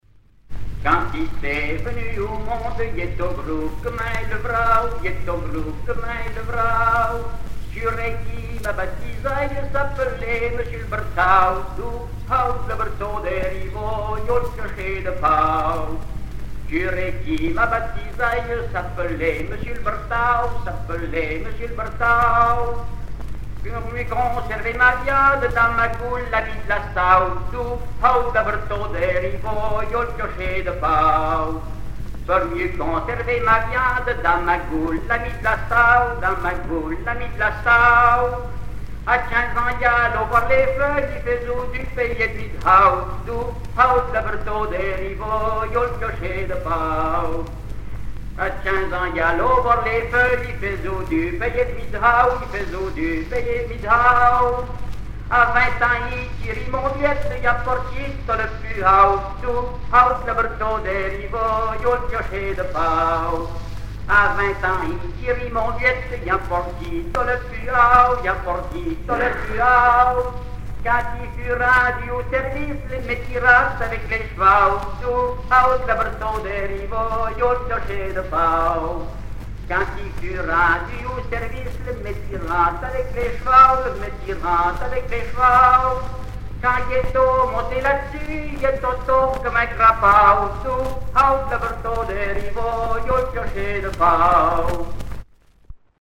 Enquête Musée de la Parole
répertoire de chansons
Pièce musicale inédite